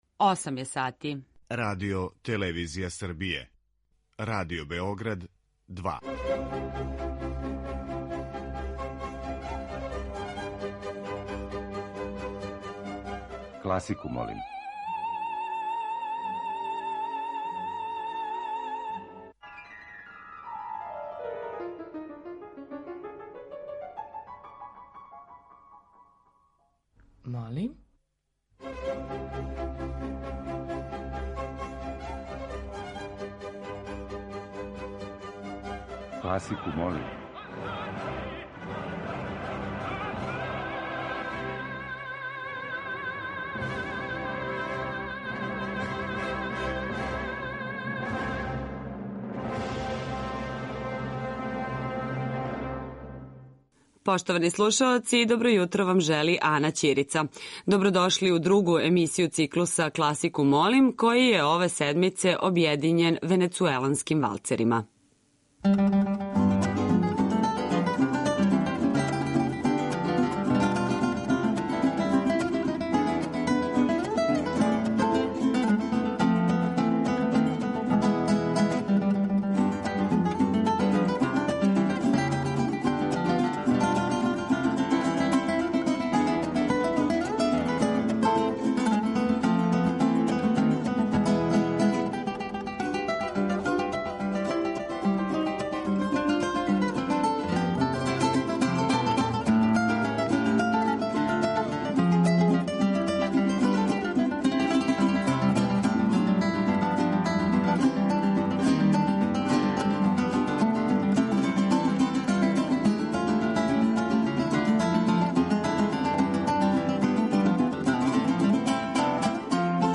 Венецуелански валцери